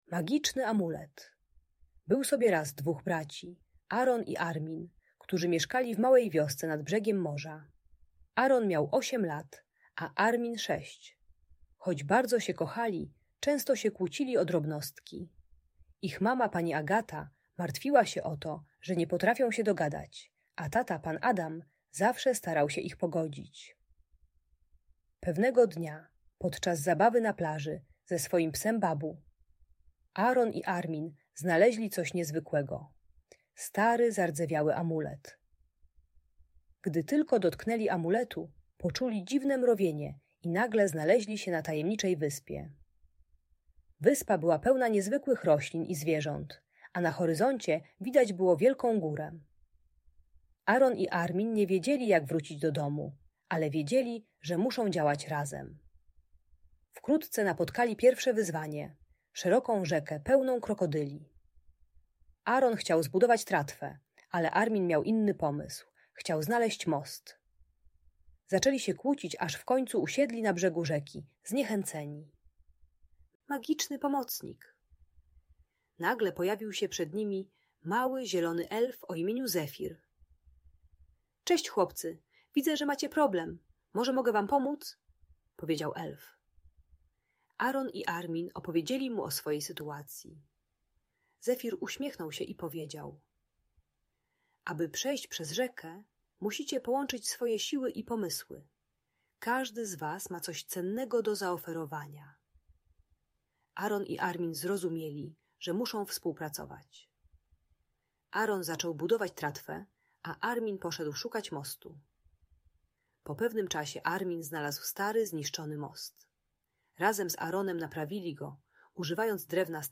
Magiczny Amulet - Rodzeństwo | Audiobajka